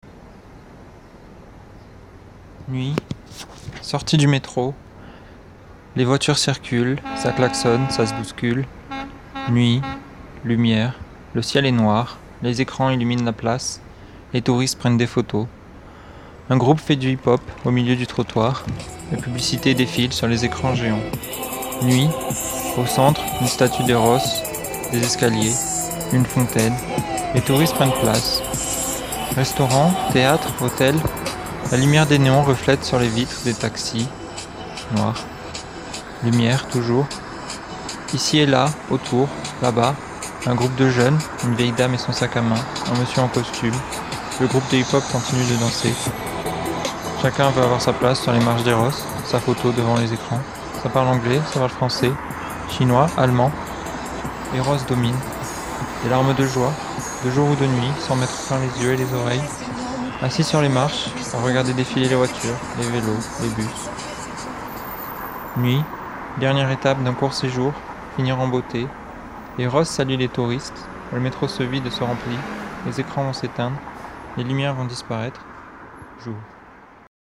Londres de nuit, à l'écoute.